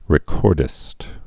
(rĭ-kôrdĭst)